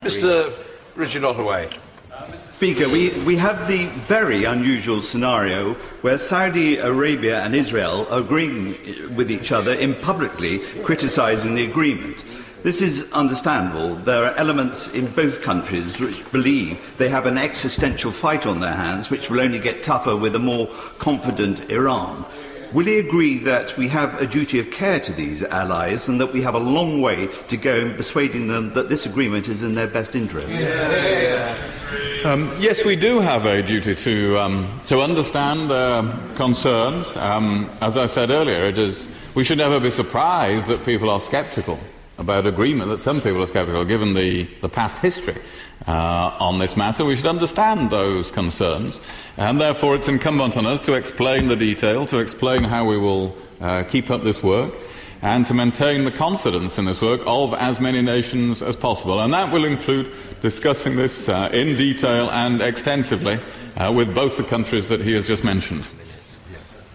House of Commons, 25 November 2013